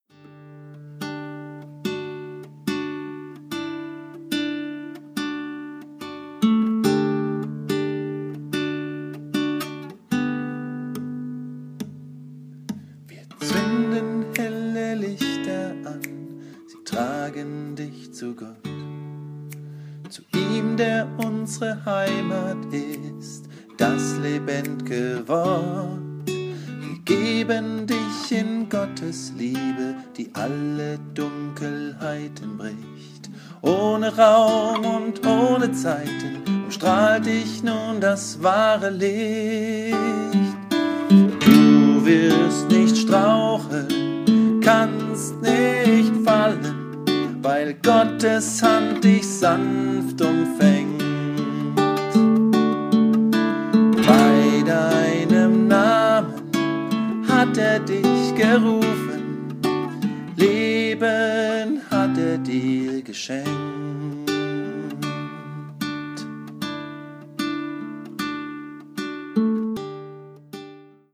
die (erste, noch demohafte) Umsetzung eines Textes von